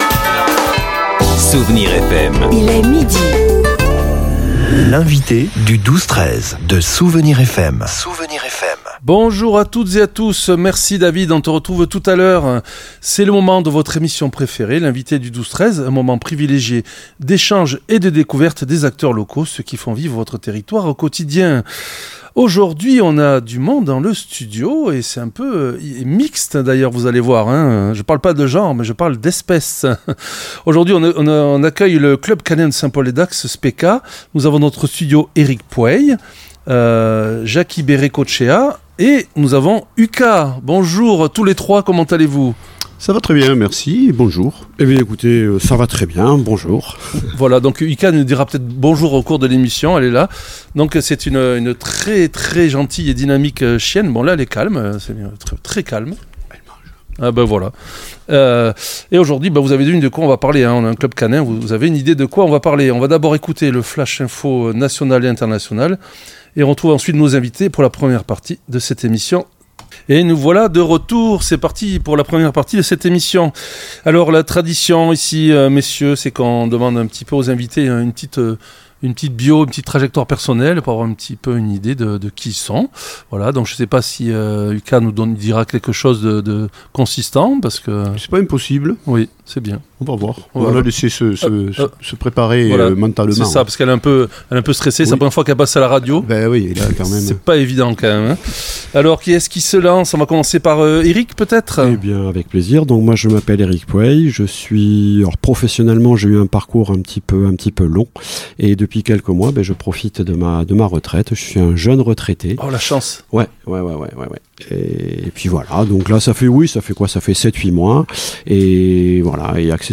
L'entretien a permis de découvrir la diversité des sports canins pratiqués au club, de l'Agility au Flyball, en passant par le Hoopers, une discipline fluide et sans sauts accessible à tous les chiens.